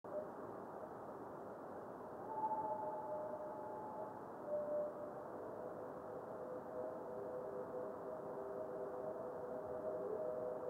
Preliminary Comments:  This significant fireball was recorded here through clouds, about 300 miles north of my observatory.
This fireball made a strong radio reflection. This movie is of the head echo only.  Note the interesting "pop" sound.